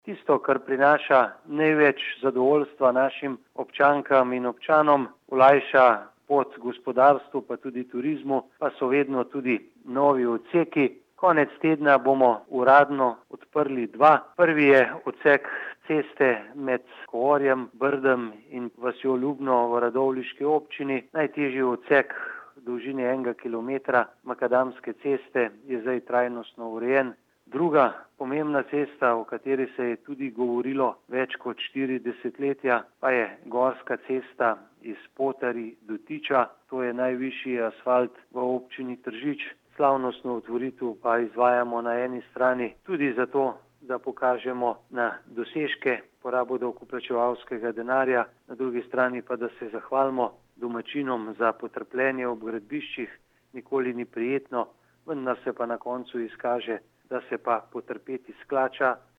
75520_izjavazupanaobcinetrzicmag.borutasajovicaobodrptjudvehodsekovcest.mp3